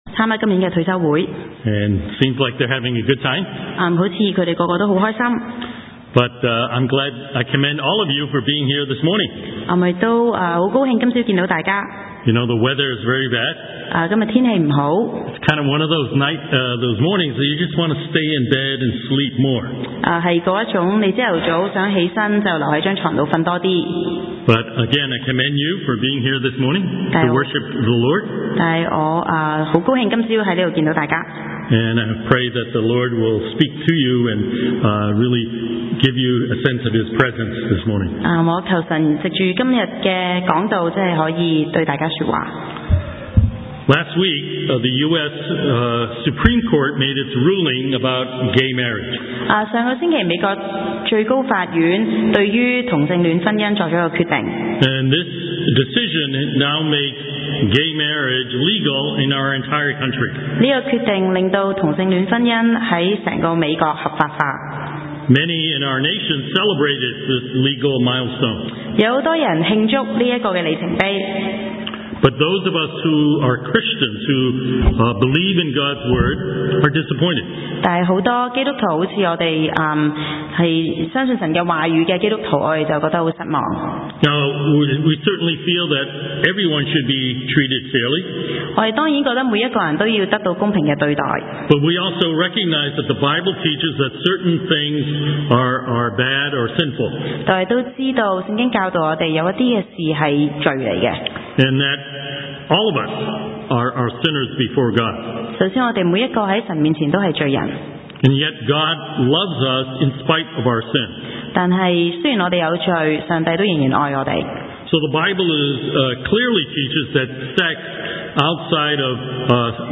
牛頓國語崇拜